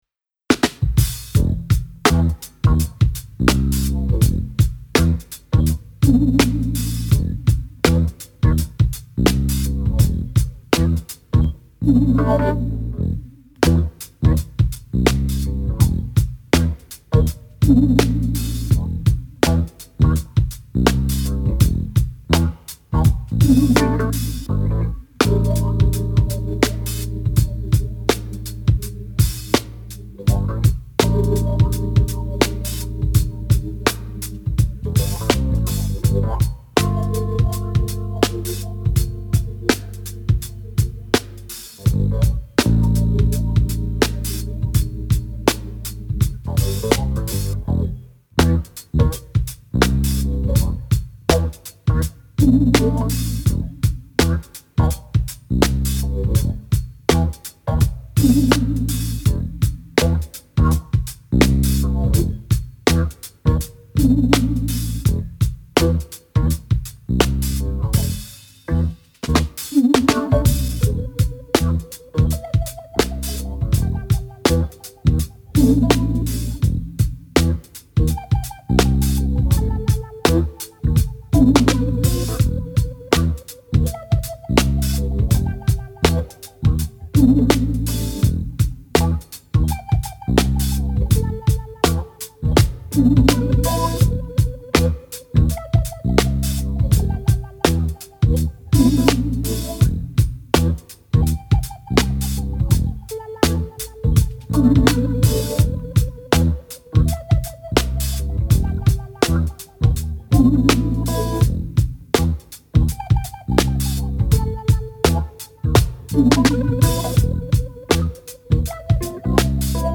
Cool funky hip hop beat with underscores.